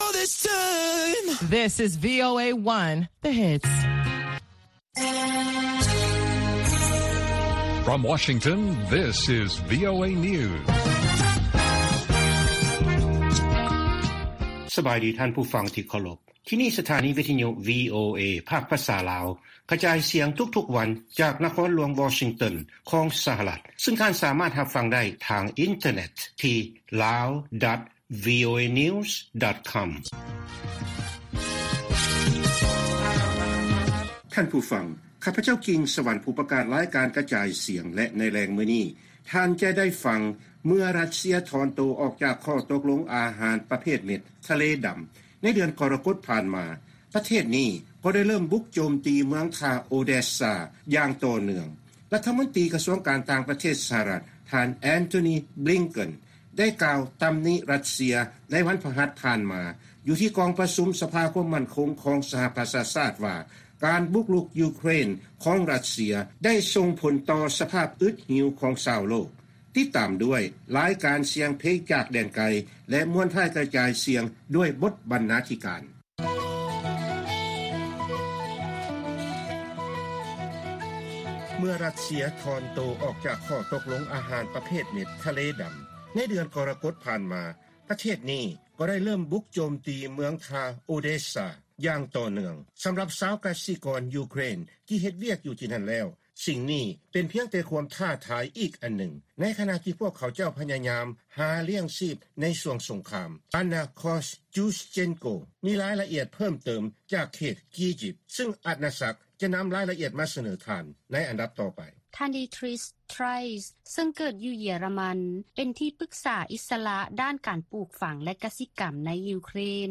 ວີໂອເອພາກພາສາລາວ ກະຈາຍສຽງທຸກໆວັນ, ບົດລາຍງານສໍາລັບມື້ນີ້ມີ: 1. ຊາວກະສິກອນເຢຍຣະມັນ ຢູ່ໃນຢູເຄຣນ ຕໍ່ສູ້ເພື່ອຮັກສາທຸລະກິດ, ແລະ ຊ່ວຍເຫຼືອກອງກຳລັງທະຫານ, 2. ຣັດເຊຍ ແຕ່ພຽງປະເທດດຽວ ສຳລັບການຮຸກຮານຕໍ່ການສະໜອງອາຫານແກ່ໂລກ, ແລະ 3. ຄະນະຜູ້ແທນຈາກສະພາແຫ່ງຊາດລາວ ຖອດຖອນບົດຮຽນອັນສໍາຄັນ ຈາກລັດຖະສະພາສະຫະລັດ.